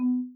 Example_ProgressSound.wav